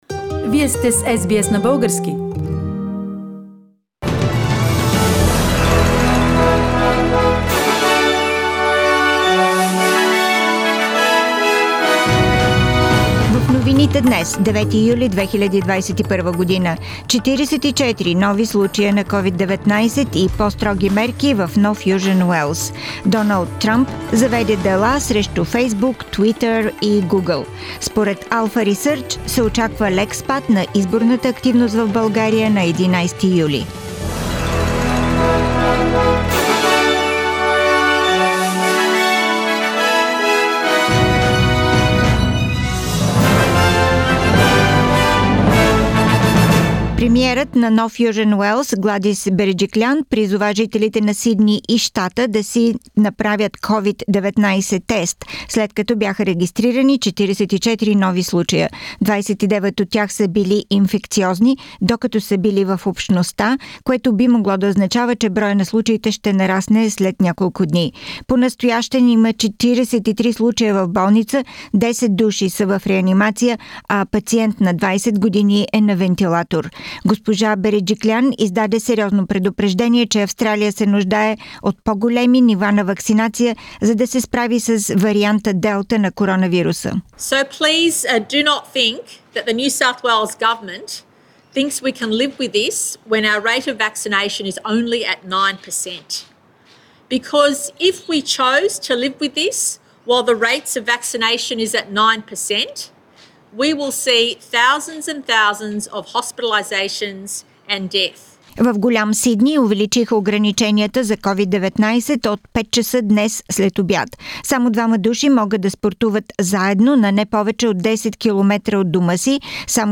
Weekly Bulgarian News – 9th July 2021